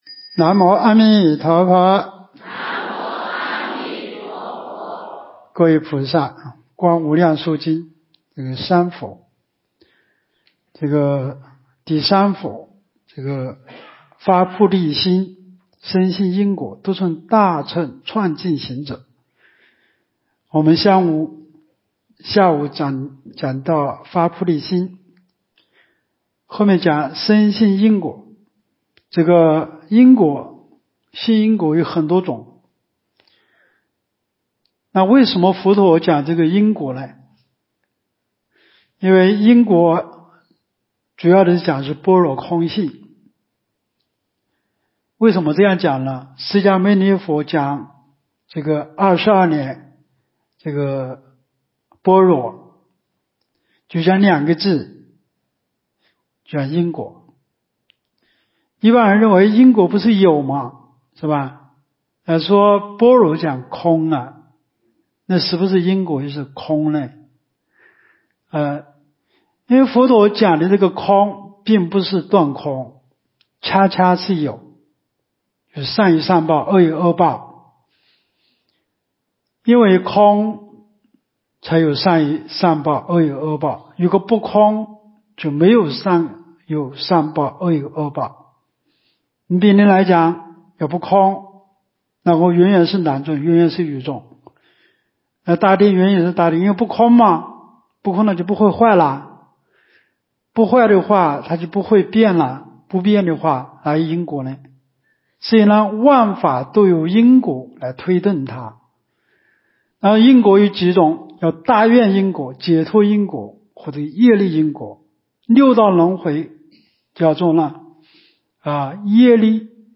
无量寿寺冬季极乐法会精进佛七开示（14）（观无量寿佛经）...